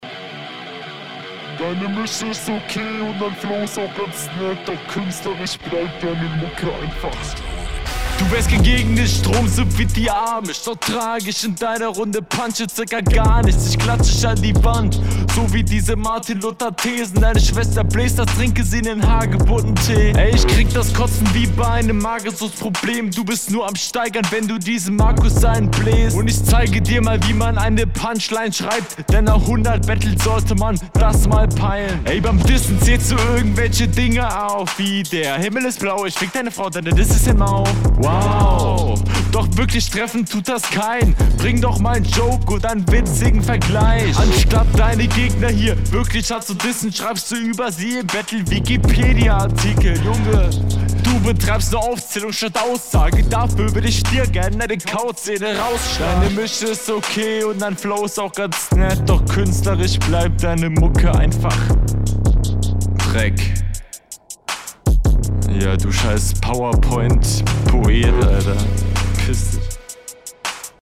Sehr monotoner, stockender FLow, inhaltlich hat das mehr von einer Hinrunde, es wird allgemein über …